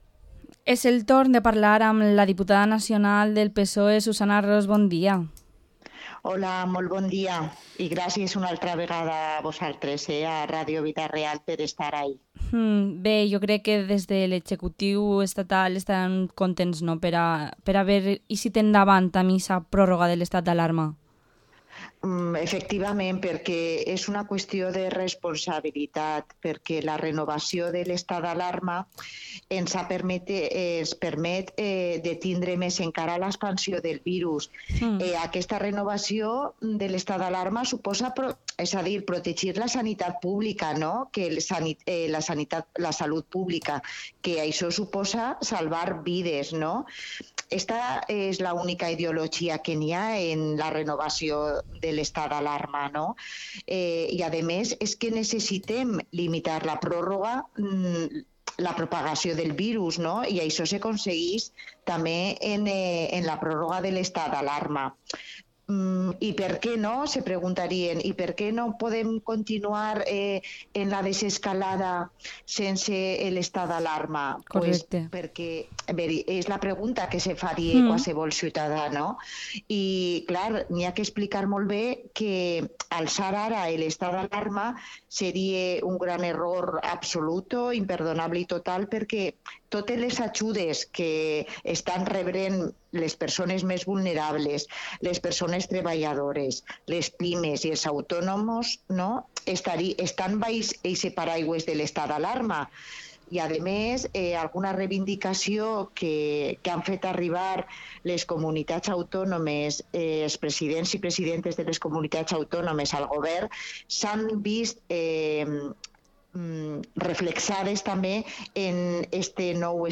Entrevista a la diputada nacional del PSPV-PSOE y miembro de la ejecutiva federal, Susana Ros